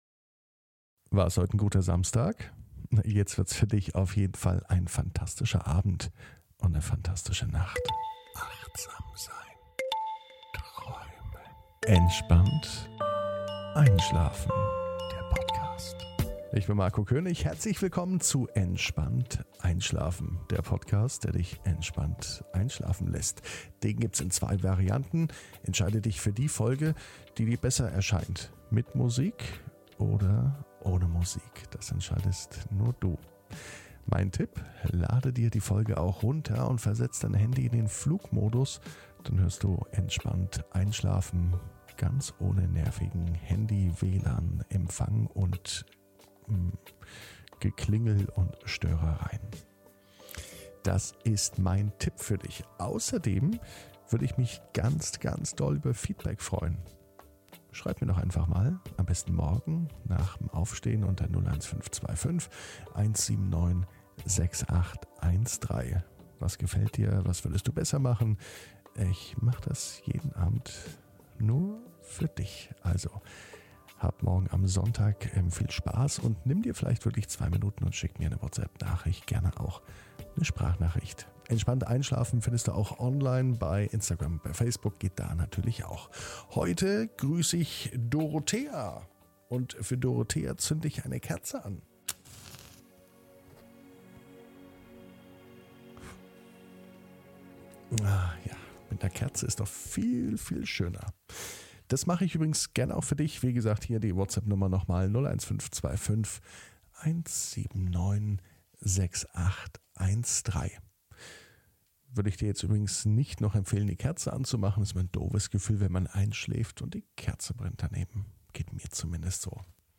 (ohne Musik) Entspannt einschlafen am Samstag, 26.06.21 ~ Entspannt einschlafen - Meditation & Achtsamkeit für die Nacht Podcast